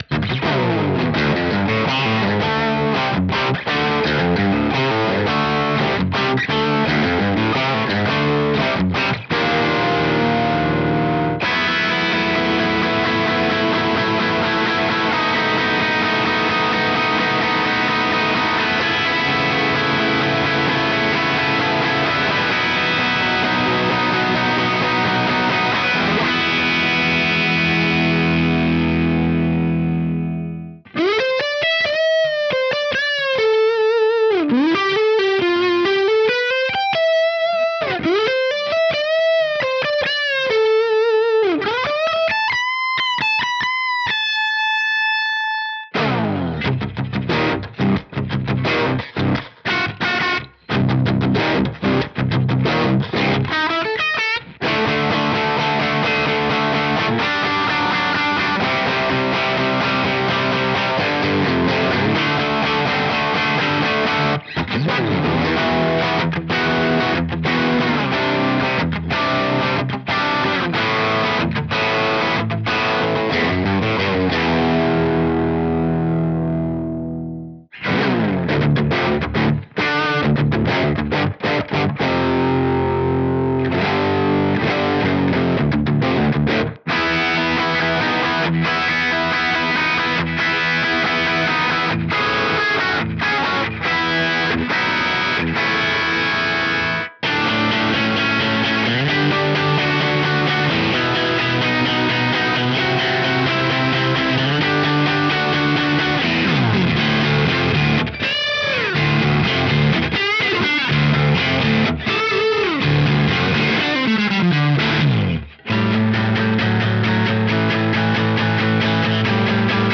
Выкладываю тест преампа JCM800 сделанного в LTSpice, всключая темброблок И моей симуляции, которая, в отличие от LTS работает в реальном времени Оба преампа в одинаковый профиль мощника и одинаковый импульс